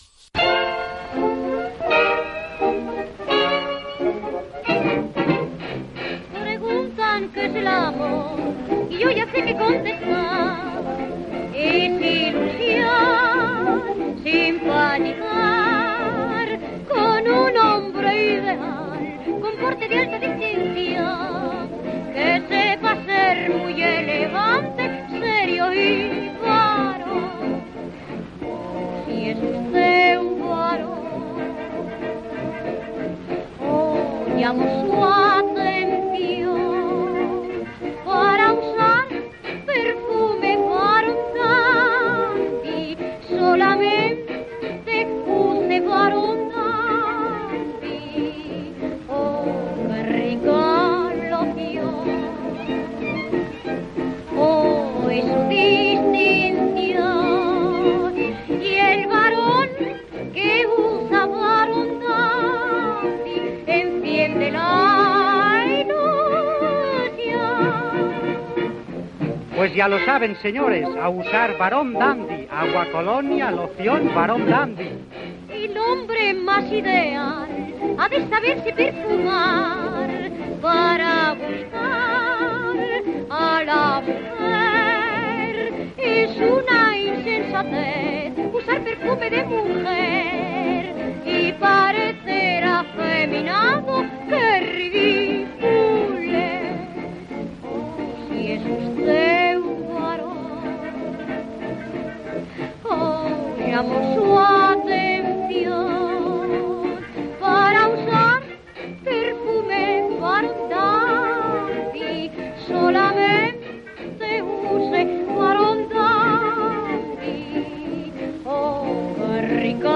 Cançó publicitària